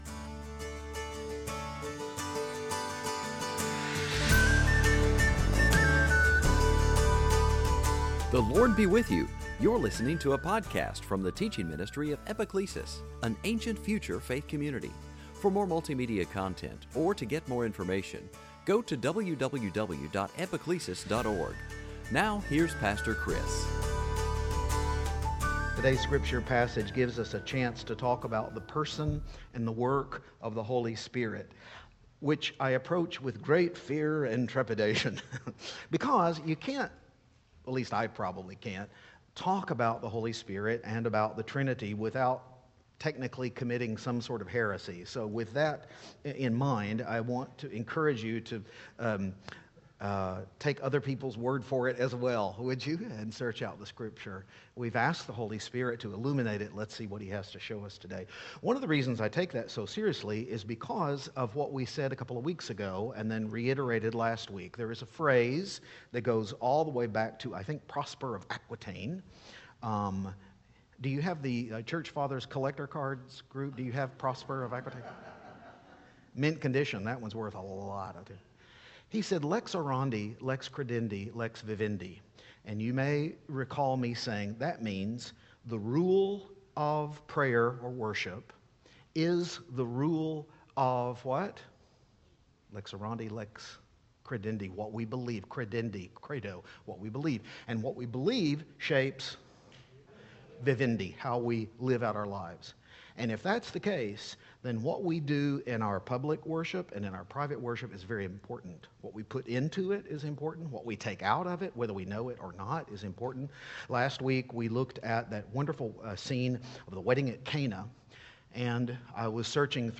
Series: Sunday Teaching
Luke 4:14-21 Service Type: Epiphany Did Jesus ever come right out and say that he was the Messiah?